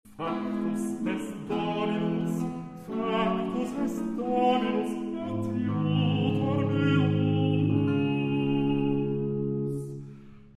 De jubelstemming, dus de driekwartsmaat is nogmaals terug. Op ‘adiutor’ komt de hoogste noot van het lied, een F.